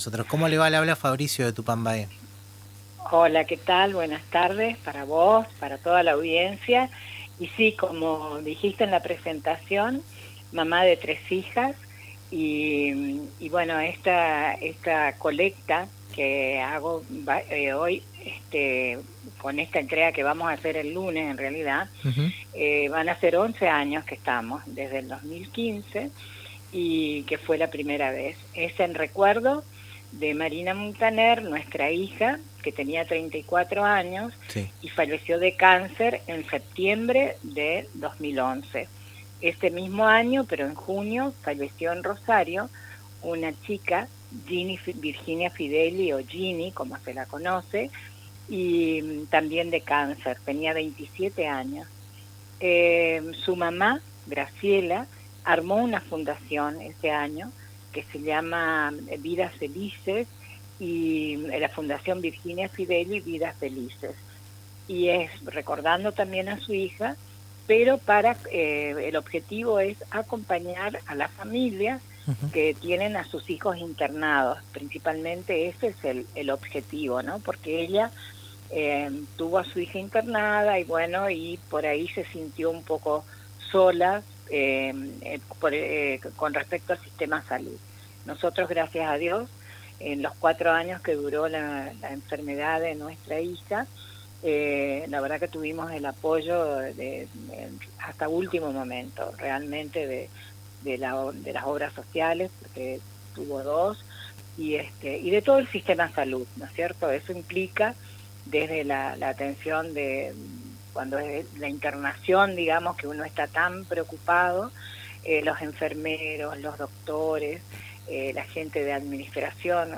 en diálogo con El Ritmo Sigue por Radio Tupambaé